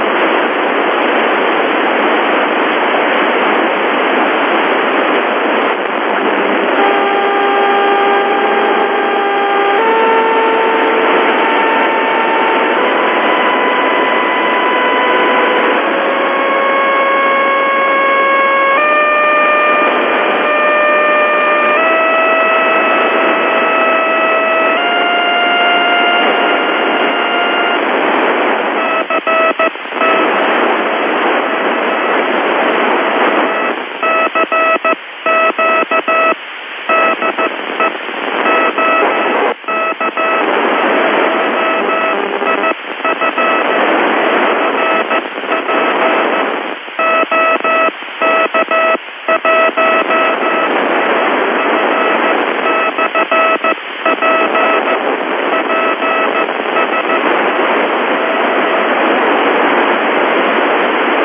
Na této nahrávce jsou tóny měněny od 400Hz po 50Hz až do 700Hz. Kromě toho, že u nižších kmitočtů je silně slyšet intermodulační produkt (asi by stálo za to, to trošku ofiltrovat), tak veliká změna je v tom, že původní nahrávka je s 50mW, kdežto ta druhá je s výkonem o 6dB více.